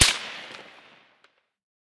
med_crack_08.ogg